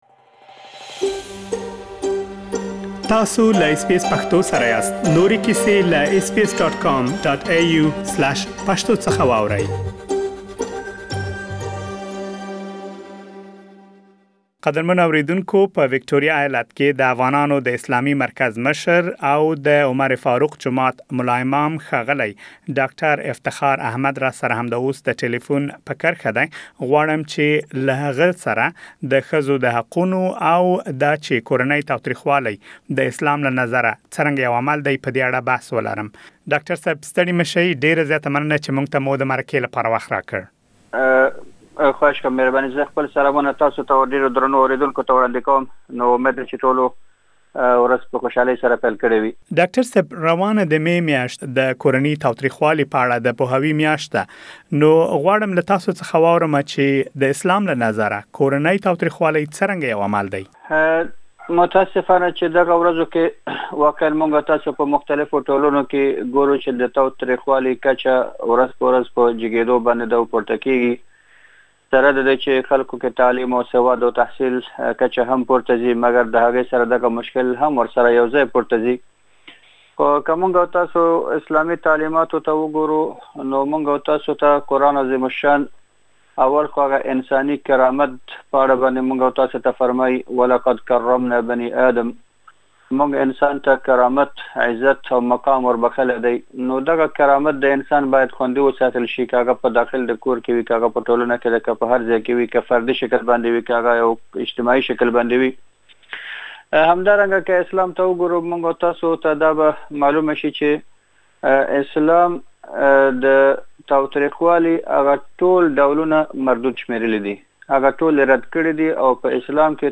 په دغې مرکې کې: